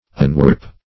Search Result for " unwarp" : The Collaborative International Dictionary of English v.0.48: Unwarp \Un*warp"\, v. t. [1st pref. un- + warp.] To restore from a warped state; to cause to be linger warped.